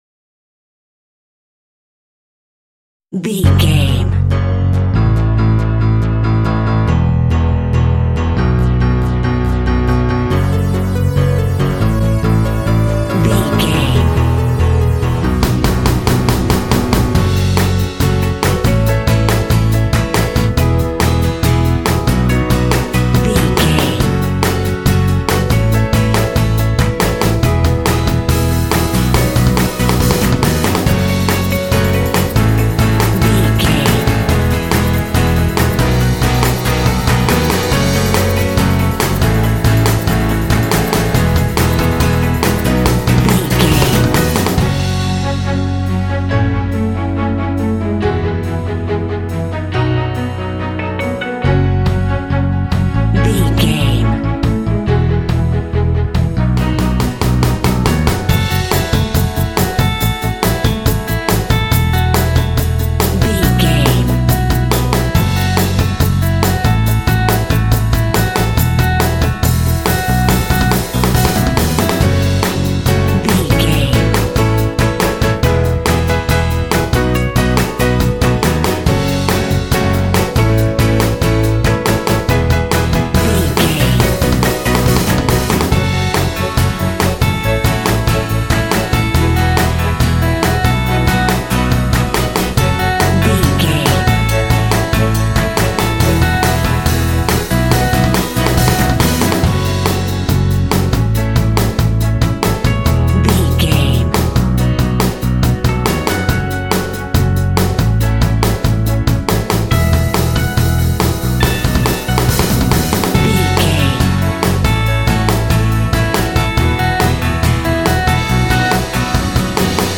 This uplifting synth-pop track will energize your game.
Aeolian/Minor
F#
motivational
driving
synthesiser
piano
bass guitar
electric guitar
strings
drums
synth-pop
indie